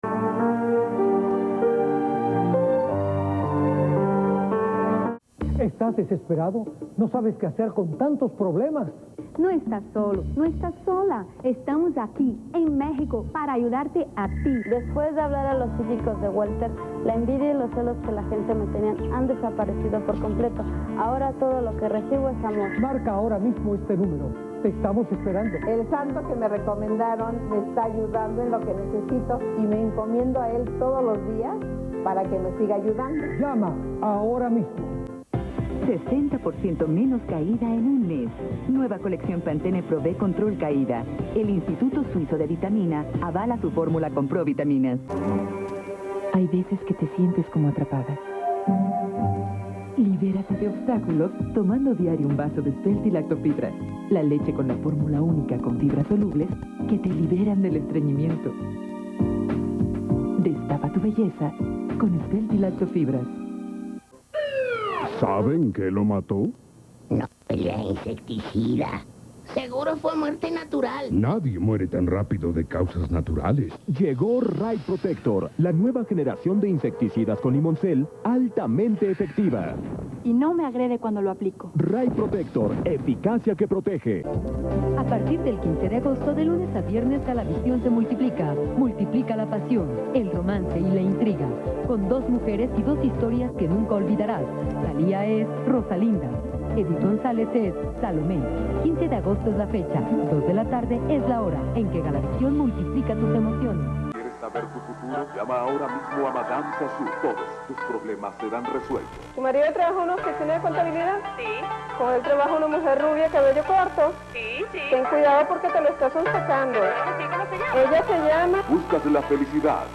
Bloque publicitario Galavisión (Agosto 2005)